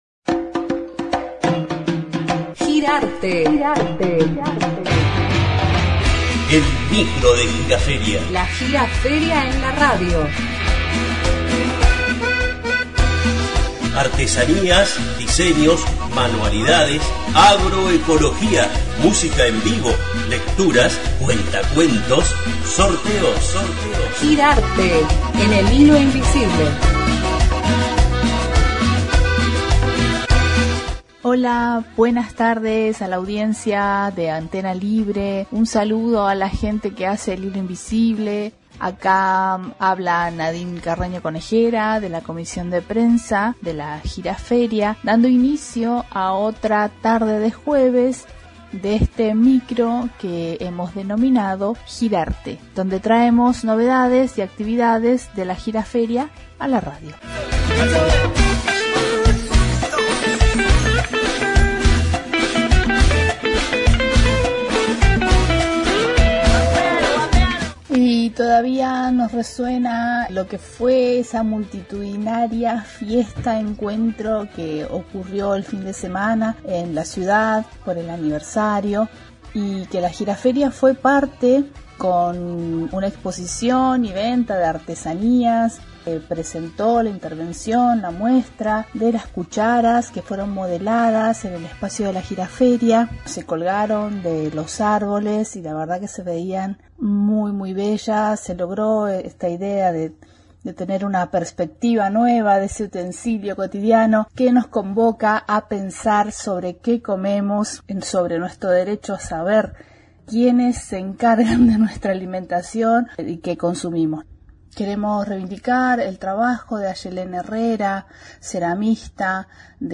Espacio de radiodifusión de las actividades de la Gira Feria, todos los jueves en El Hilo Invisible.